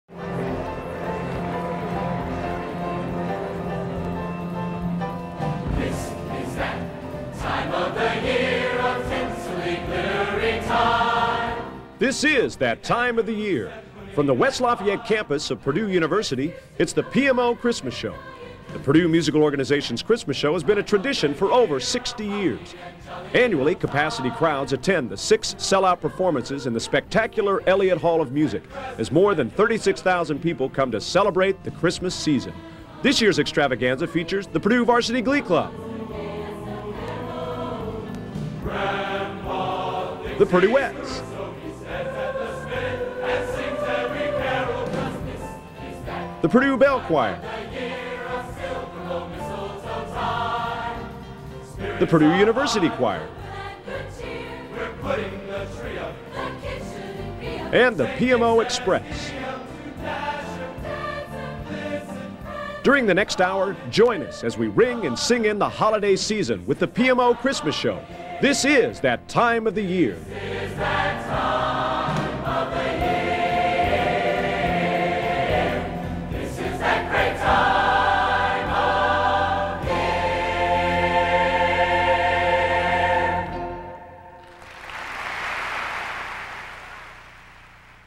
Collection: Christmas Show 1994
Location: West Lafayette, Indiana
Genre: | Type: Christmas Show |Director intros, emceeing |